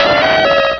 Cri de Donphan dans Pokémon Rubis et Saphir.